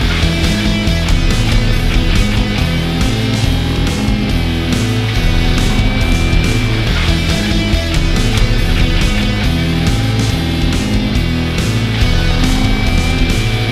Warning: This artwork incorporates iAMF frequency elements intended for subconscious conditioning.
These ‘Infinity L00p’ soundscapes, crafted with precision, resonate with the listener’s subconscious, creating an auditory experience that lingers long after the final note.